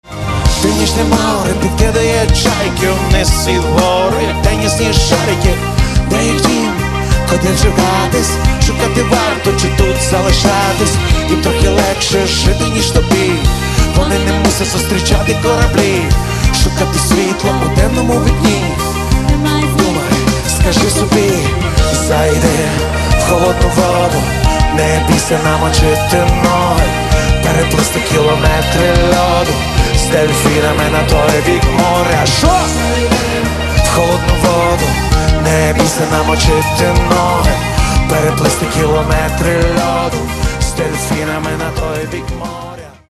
Каталог -> Рок и альтернатива -> Просто рок
записанный 4 апреля 2014 года концерт в Киеве